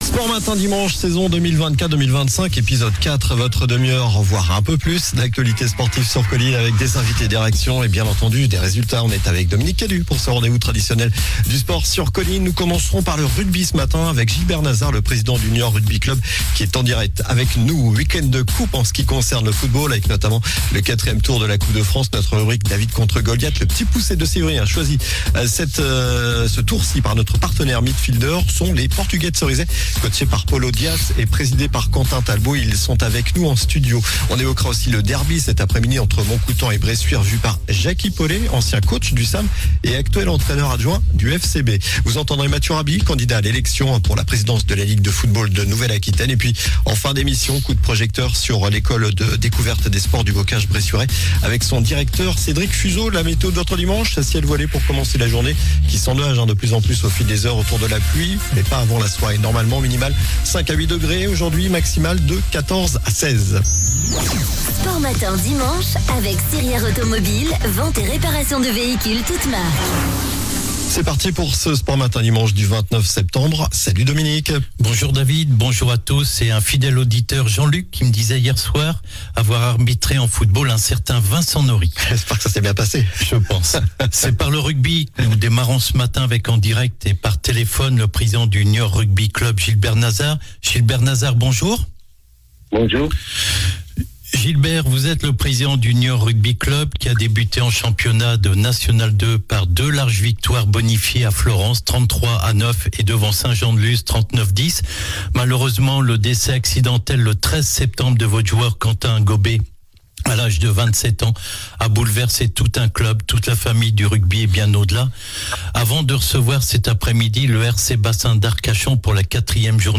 ils sont avec nous en studio